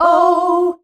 OUUH  F.wav